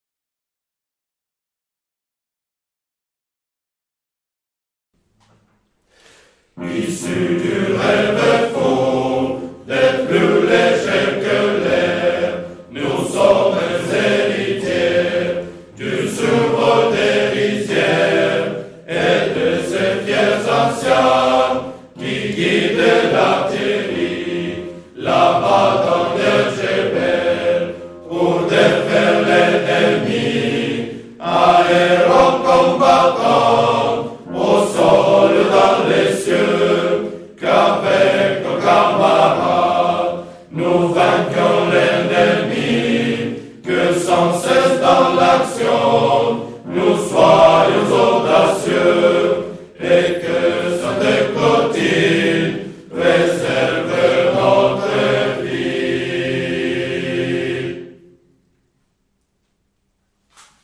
Notre chant